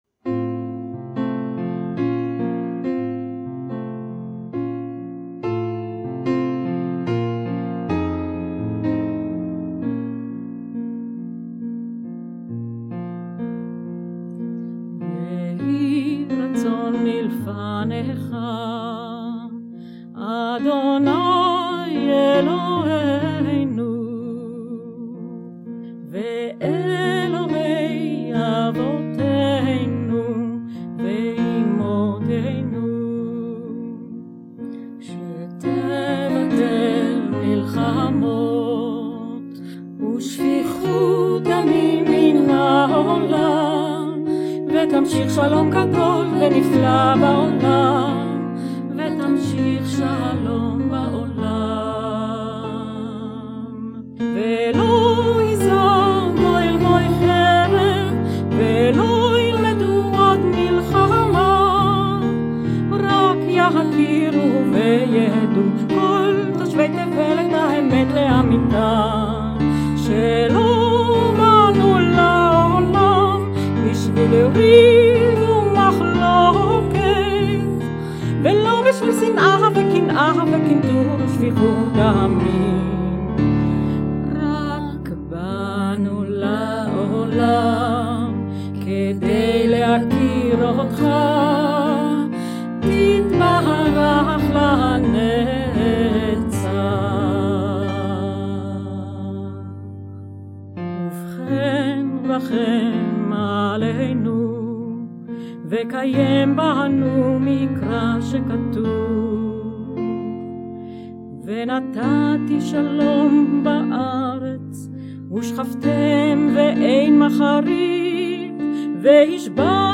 שיר "תפילה לשלום"